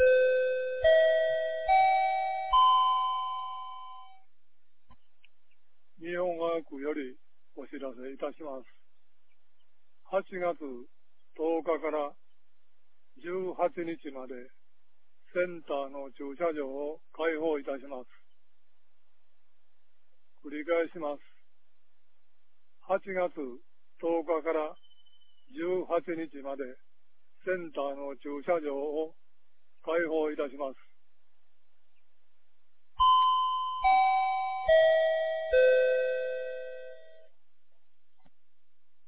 2019年08月09日 07時01分に、由良町より三尾川地区へ放送がありました。
放送音声